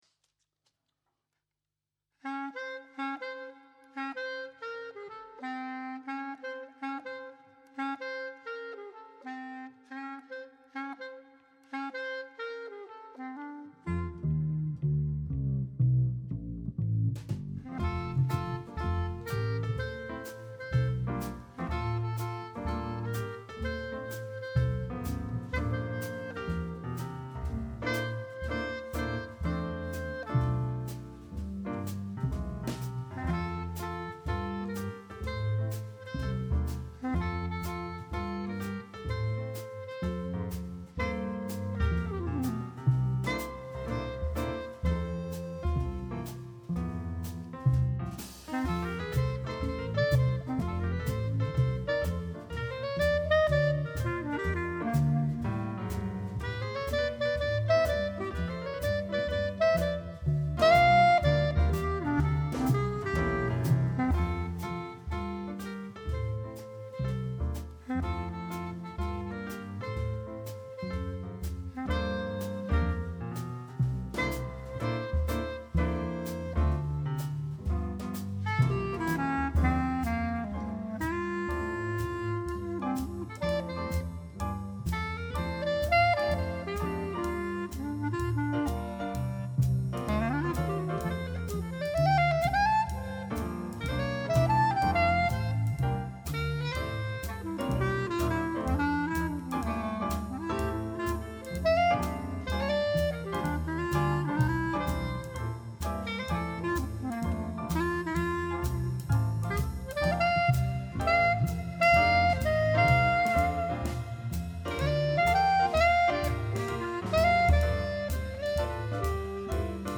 vocals
sax, tb …
piano
bass
drums
Probe vom 24.1.26